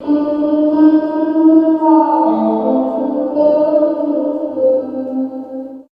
95 GTR 1  -R.wav